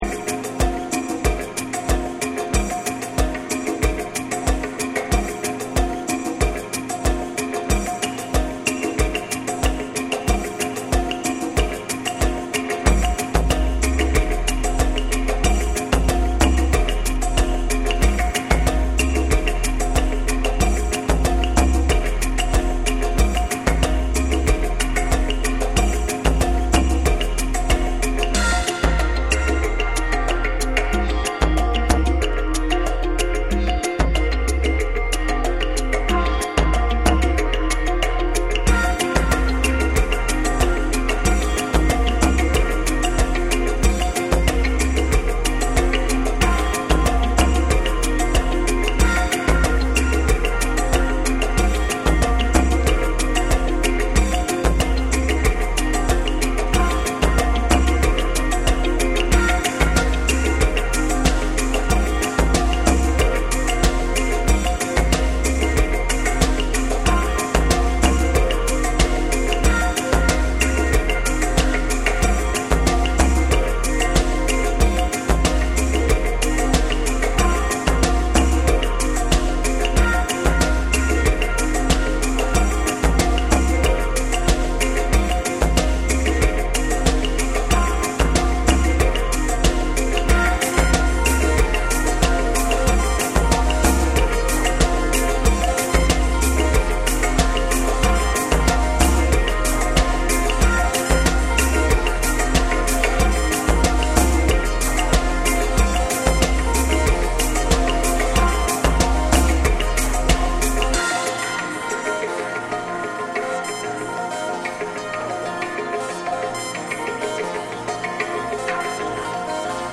生演奏の質感とエレクトロニックなプロダクションが自然に溶け合った、柔らかく心地よいバレアリック・サウンドを展開。
TECHNO & HOUSE